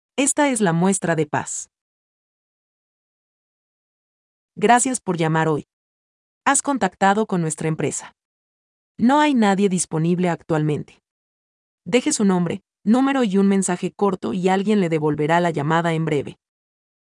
7. Paz / AI Voiceover in Spanish VO007  $29 / up to 50 words
Let us have your script recorded in a female Spanish voice, using the latest in AI technology.